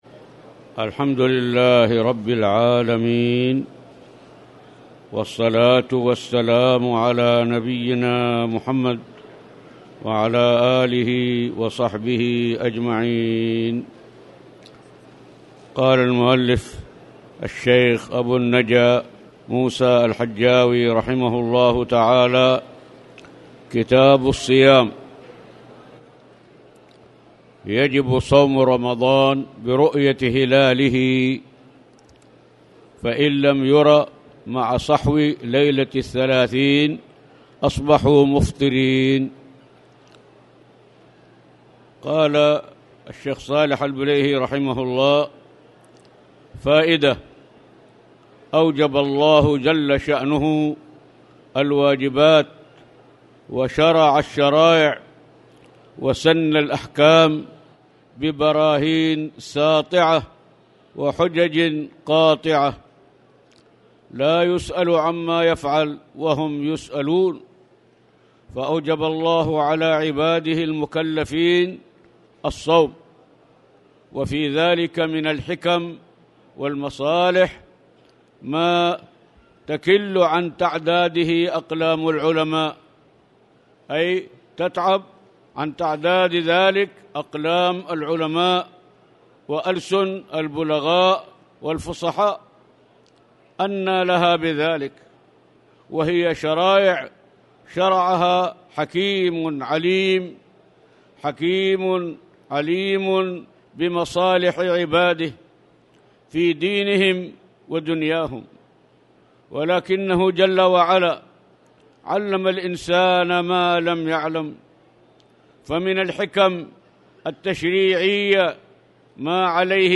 تاريخ النشر ١٠ شوال ١٤٣٨ هـ المكان: المسجد الحرام الشيخ